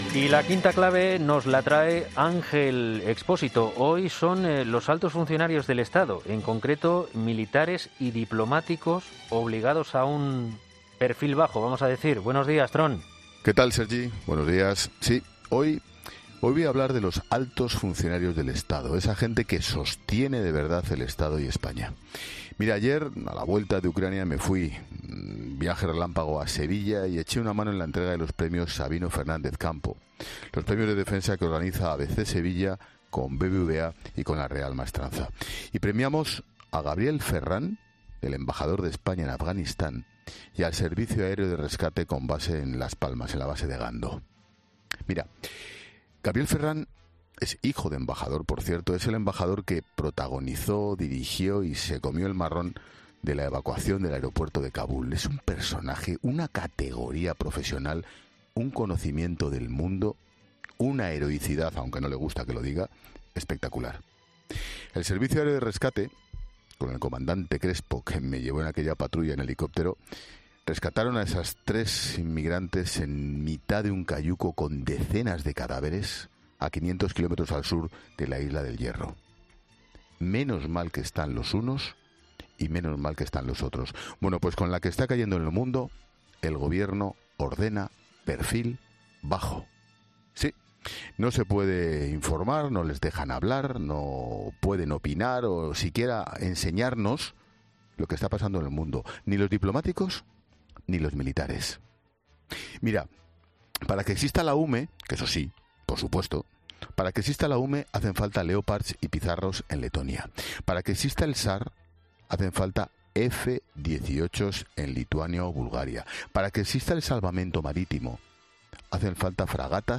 Ángel Expósito ha recordado en su sección 'El Paseíllo del Tron' en 'Herrera en COPE' a los galardonados con el IX premio Sabino Fernández Campo